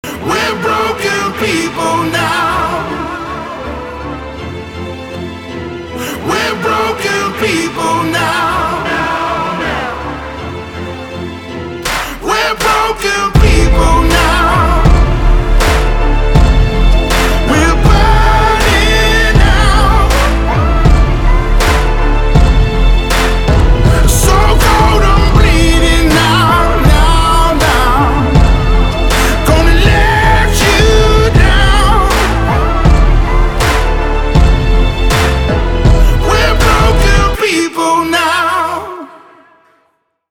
• Качество: 320, Stereo
мужской вокал
Хип-хоп
сильные
alternative